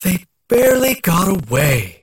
49 KB Viscous voice line (unfiltered) - They barely got away. 1
Viscous_near_miss_02_unfiltered.mp3